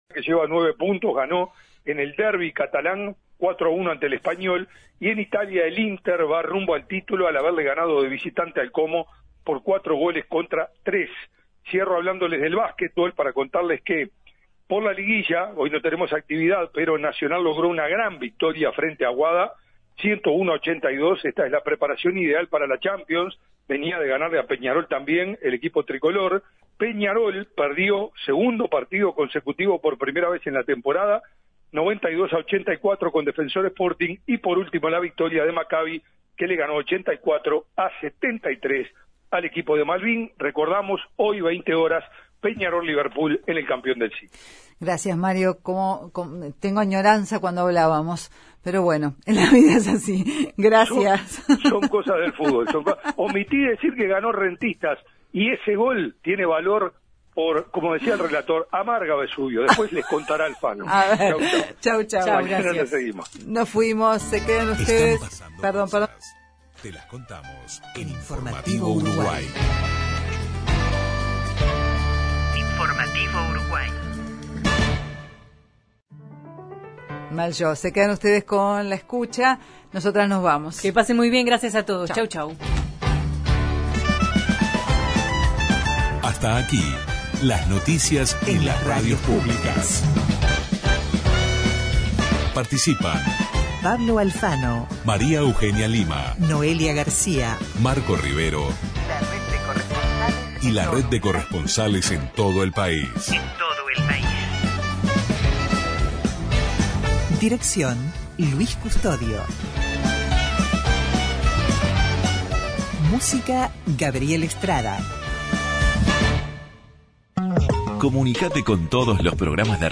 Columna internacional